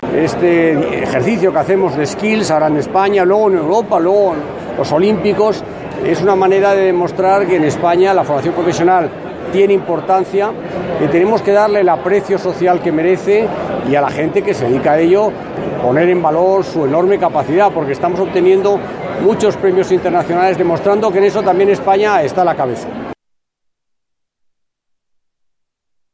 Declaración del ministro de Educación, Cultura y Deporte, Íñigo Méndez de Vigo, durante su visita a AULA 2017 Audio